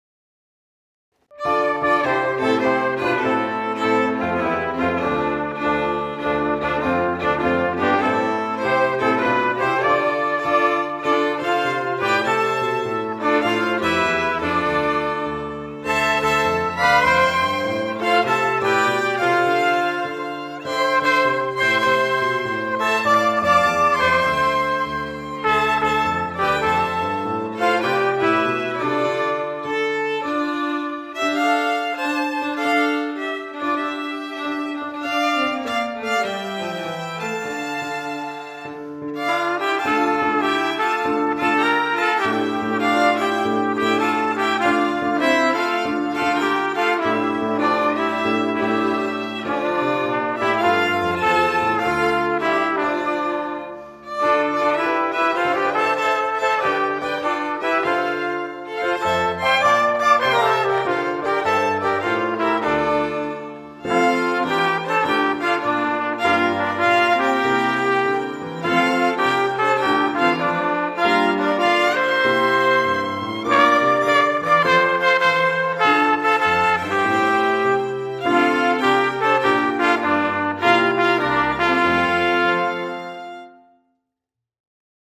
1 Instrumental